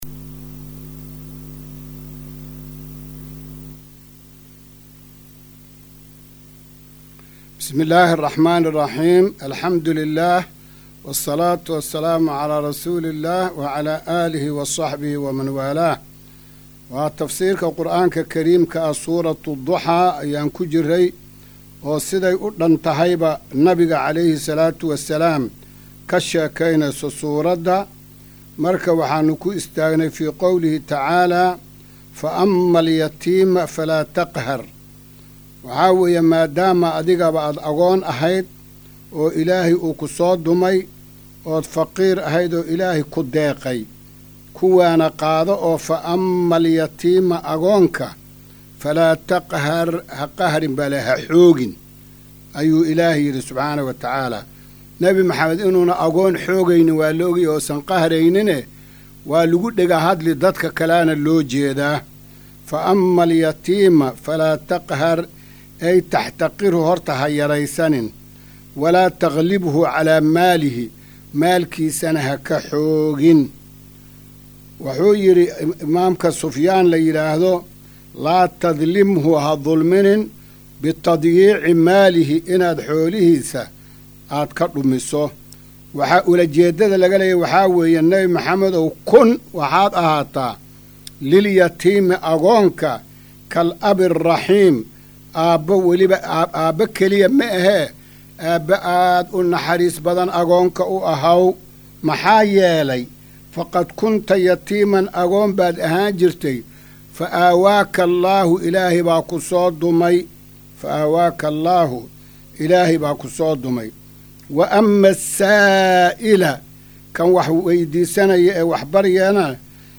Maqal:- Casharka Tafsiirka Qur’aanka Idaacadda Himilo “Darsiga 287aad”